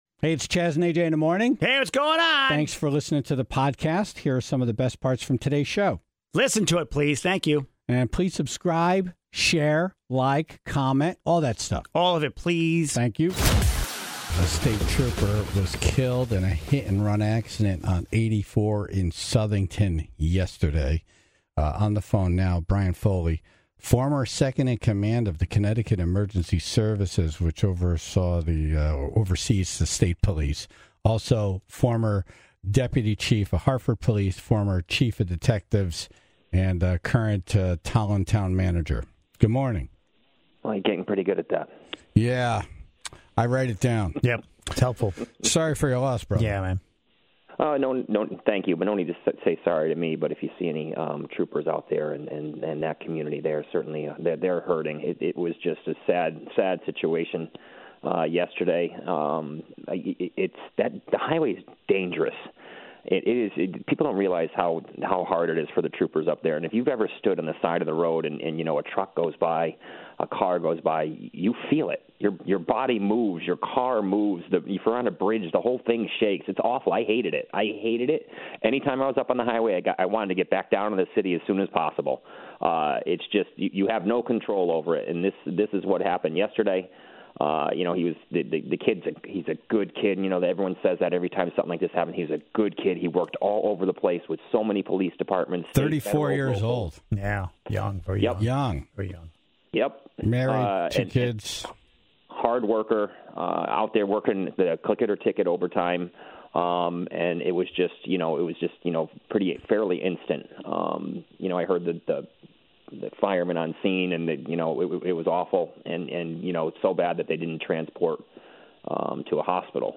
the Tribe called in with their own (mostly positive!) experiences and advice.